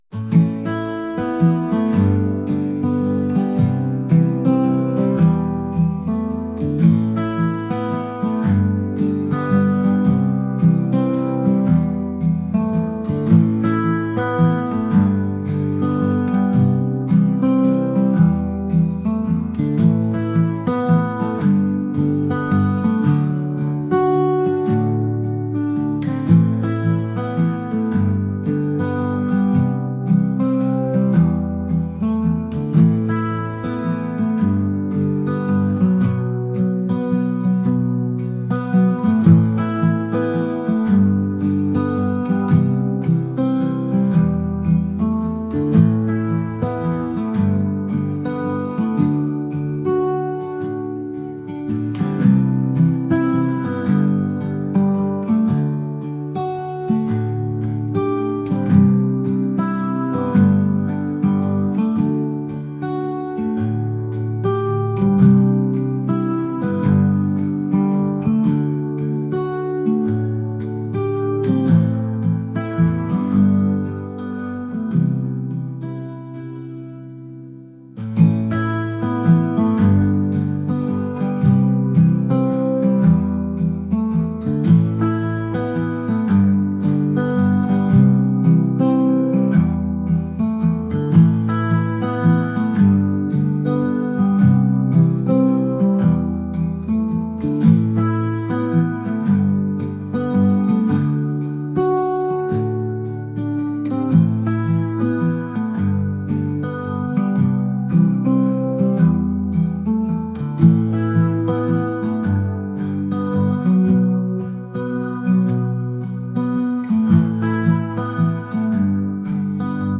acoustic | indie | folk